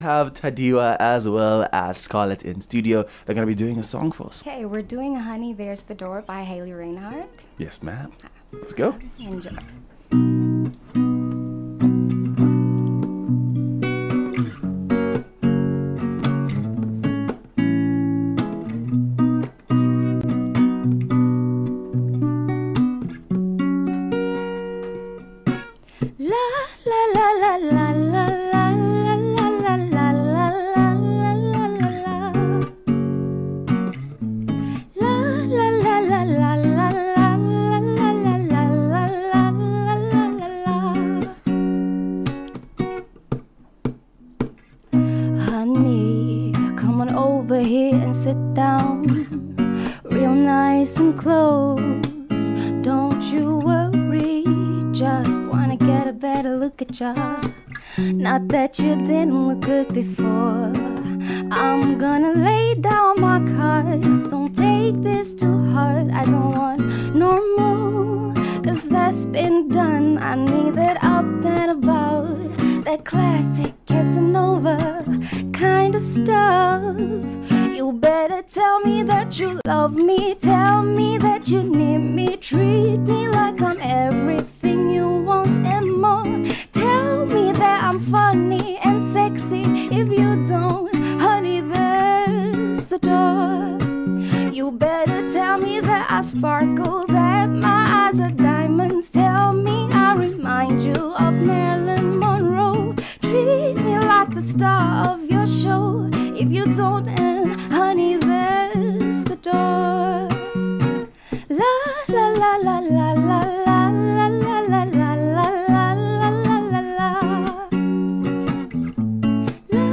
acoustic session
local vocalist
Guitarist
in studio doing some live covers...